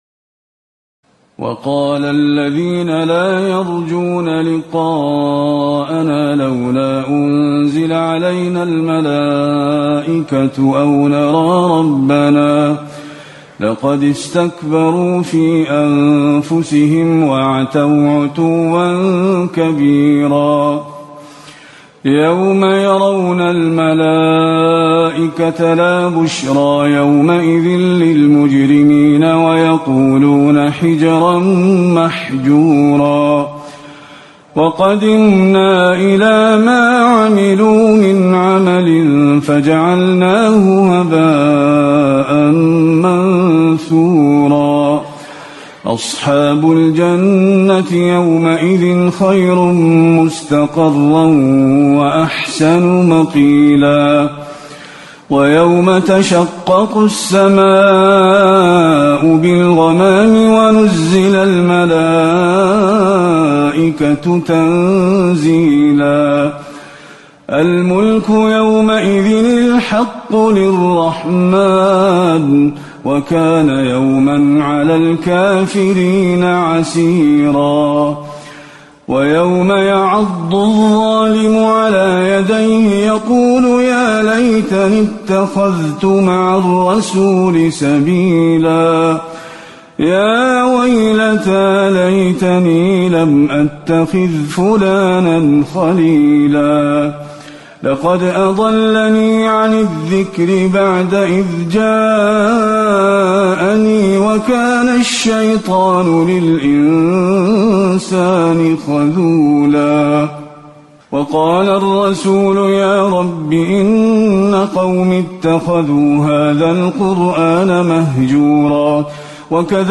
تراويح الليلة الثامنة عشر رمضان 1437هـ من سورتي الفرقان (21-77) و الشعراء (1-104) Taraweeh 18 st night Ramadan 1437H from Surah Al-Furqaan and Ash-Shu'araa > تراويح الحرم النبوي عام 1437 🕌 > التراويح - تلاوات الحرمين